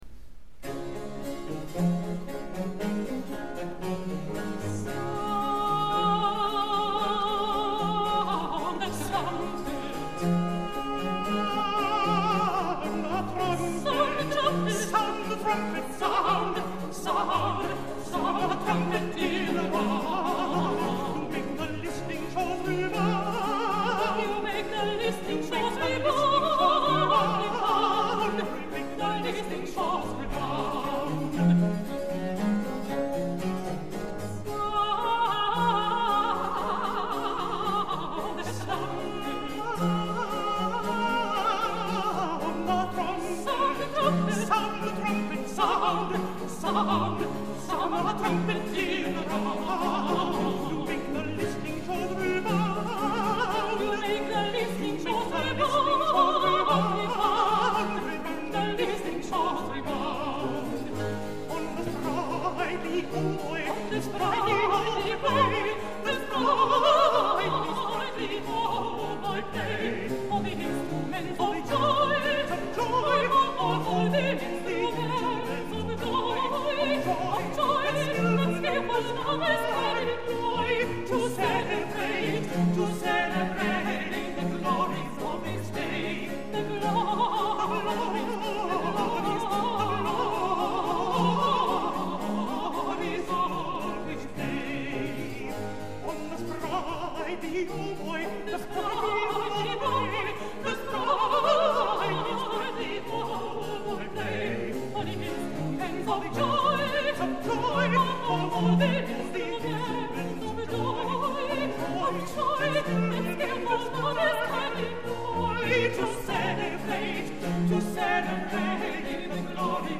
这是二人在2005年11月21日同台演出的录音，除独唱外，音乐会穿插演奏巴洛克时期一些令人喜悦的作品。